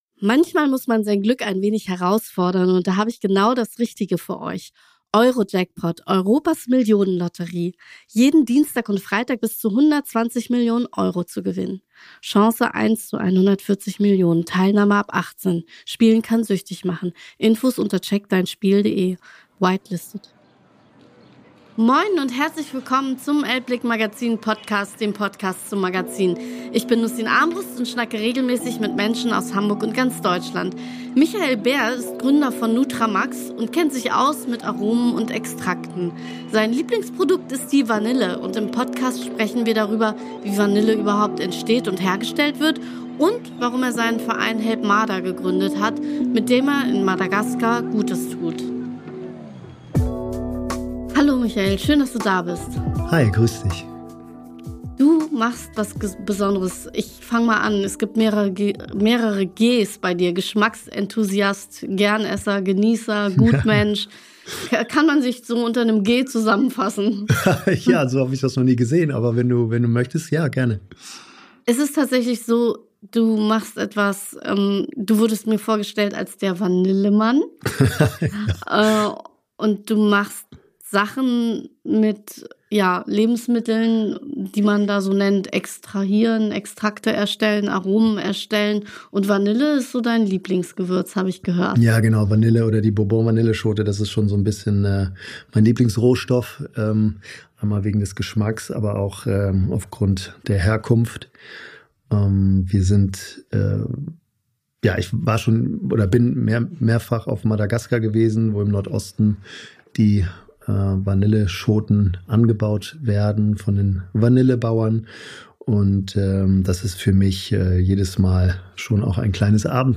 Ein spannendes Gespräch über Aromen, fairen Handel und Gutes tun.